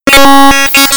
Text-Message or Videogame-Jump